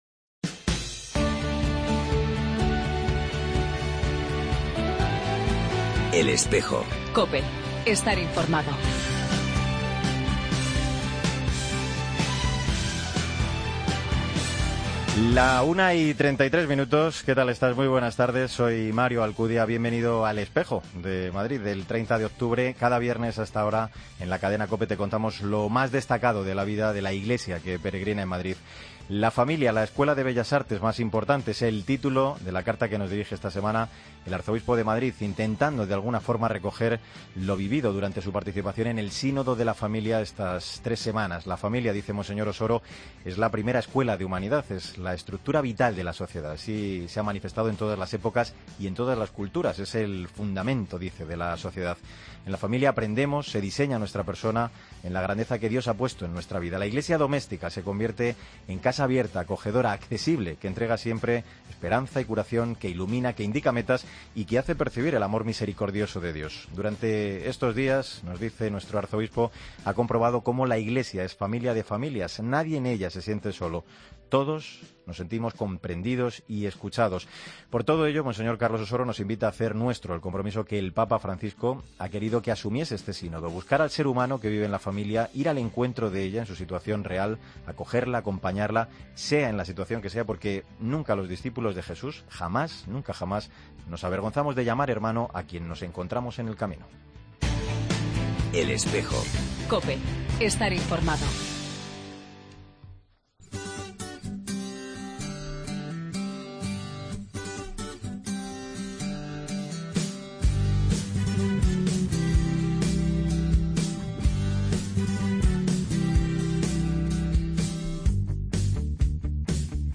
AUDIO: Entrevista a monseñor Fidel Herráez, nuevo arzobispo de Burgos;13º Encuentro Escuelas Católicas; la reunión del Grupo...